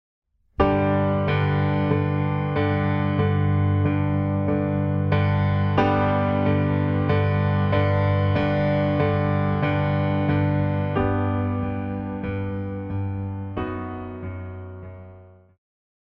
古典,流行
小提琴
钢琴
演奏曲
世界音乐
仅伴奏
没有主奏
没有节拍器